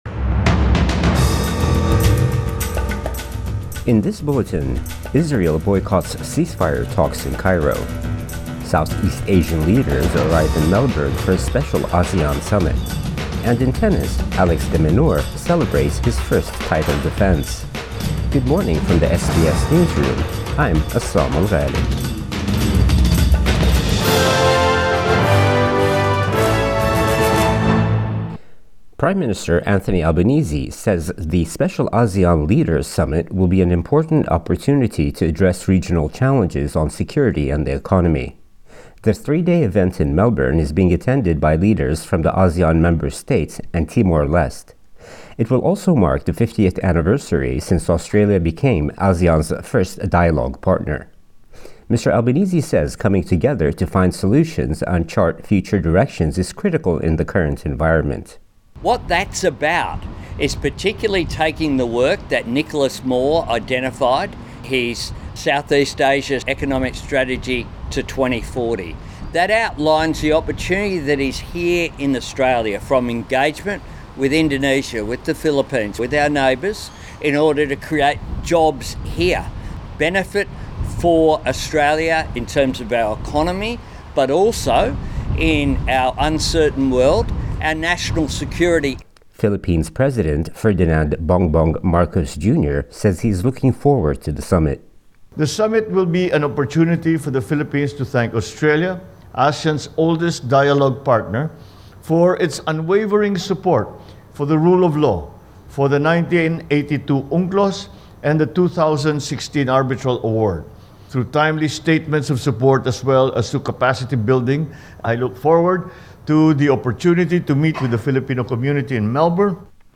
Morning News Bulletin 4 March 2024